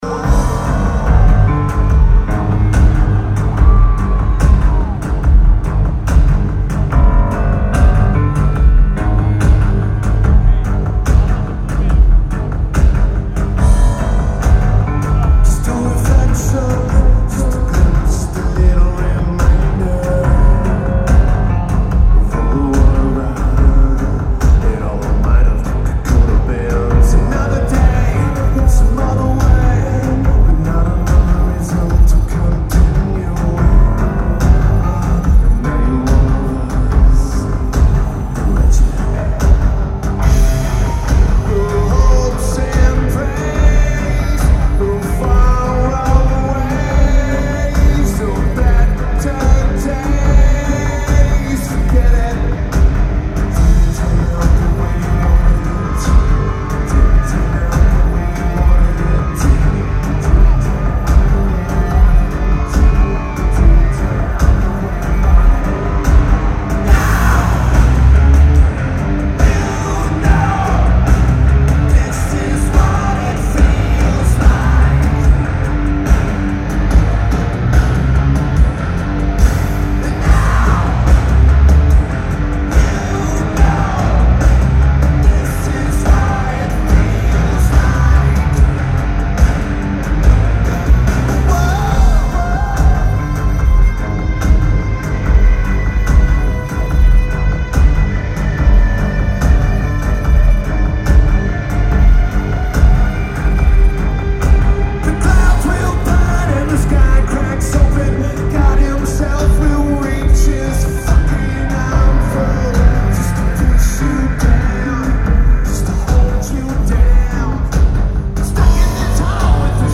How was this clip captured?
Allstate Arena Lineage: Audio - AUD (Schoeps MK4s + N-Box + Sony TCD-D100) A fantastic audience recording.